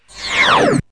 1wipe18.mp3